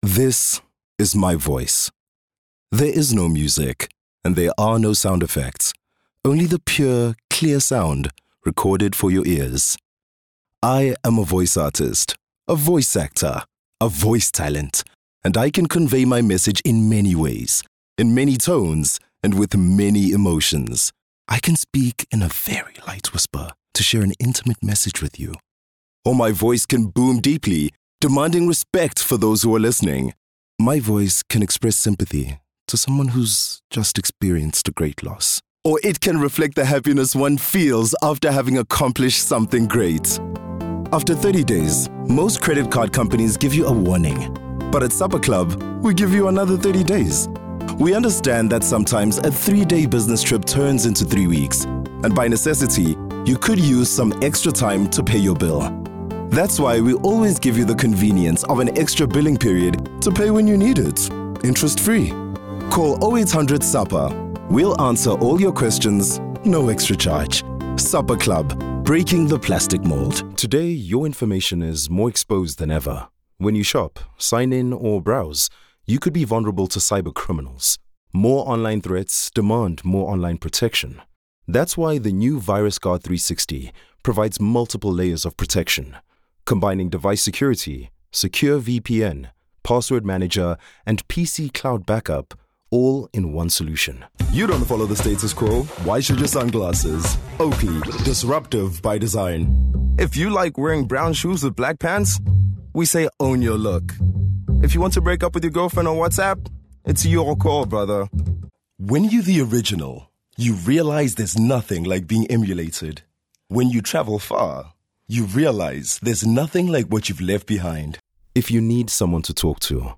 Showreel
Male / 30s, 40s / English / South African Showreel https